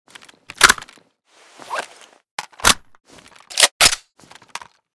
fn2000_reload_empty.ogg.bak